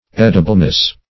Edibleness \Ed"i*ble*ness\, n.
edibleness.mp3